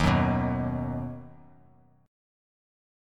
Ebm#5 chord